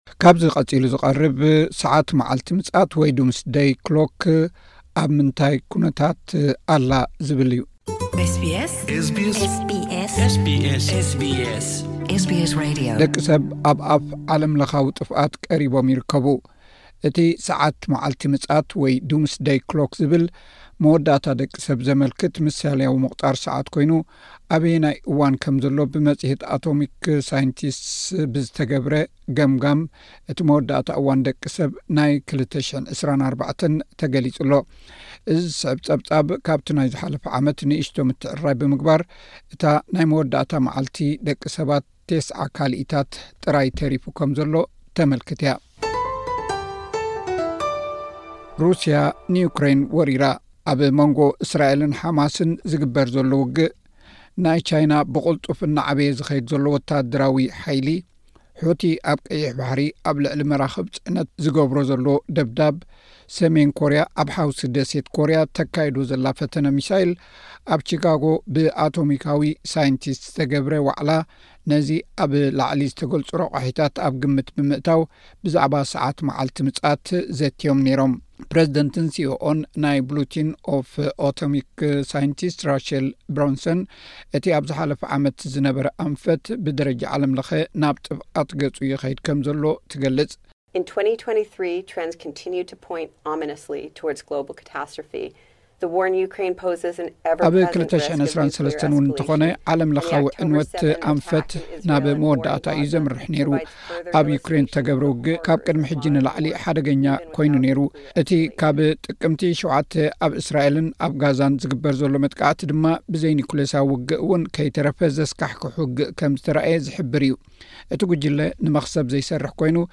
እዚ ዝስዕብ ጸብጻብ ነዚ ዝገልጽ እዩ።